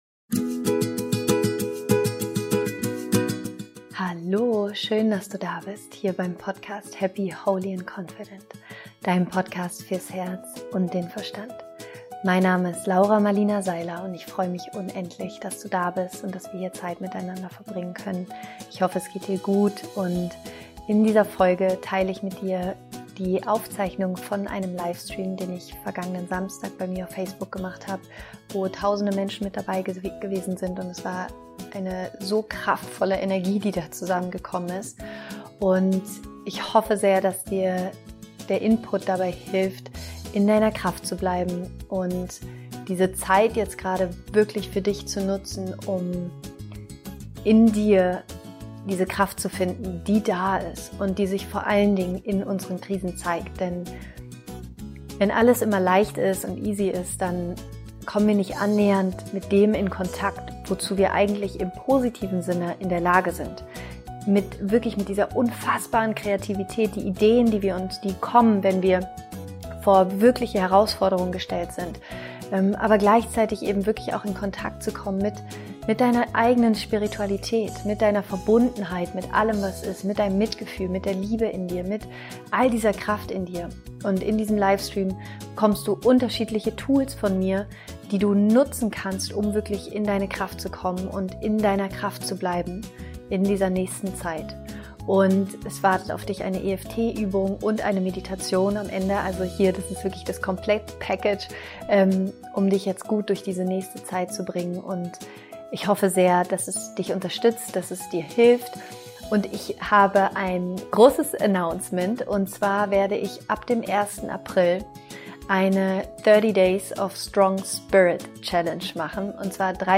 In dieser Folge teile ich mit dir die Aufzeichnung von einem Livestream, den ich vergangenen Samstag bei mir auf Facebook gemacht habe und bei dem tausende Menschen mit dabei gewesen sind. Ich erzähle dir meine Gedanken zu dieser Krise und wie wir sie für uns nutzen können.
Am Ende der Folge wartet eine kraftvolle EFT Session und eine Meditation auf dich, um dich mit deiner Kraft zu verbinden und damit du in dieser herausfordernden Zeit im Vertrauen bleiben kannst.